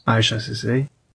Isha Isatu Sesay (/ˈʃə səˈs/